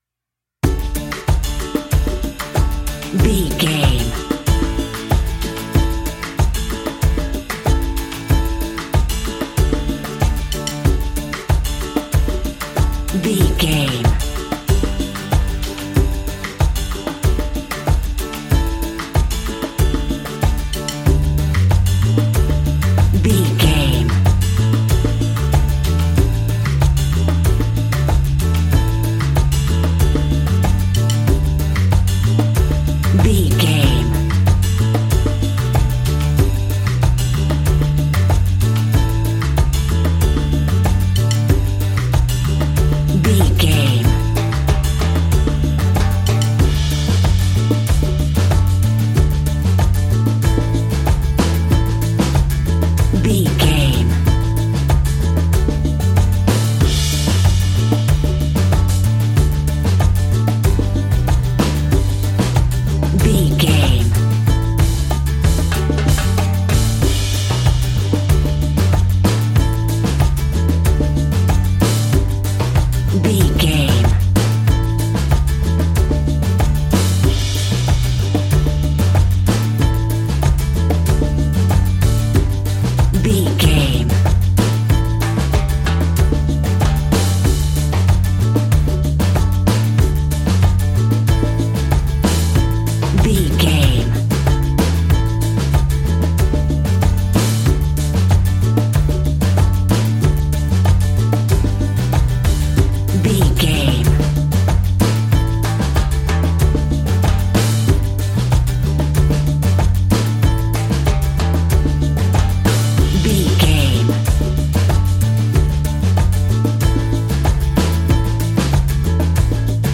Ionian/Major
D
cheerful/happy
mellow
drums
electric guitar
percussion
horns
electric organ